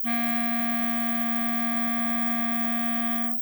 [link] represents the sound of a clarinet re-quantized with 8 bits. A dithering and a noise-shaping were applied to the sound.
This noise is anyway not audible.
clarinet at 8 bit with noise shaping that contain the clarinet sounds represented in [link] , [link] , [link] , e [link] , respectively.